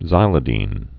(zīlĭ-dēn, -dĭn, zĭlĭ-)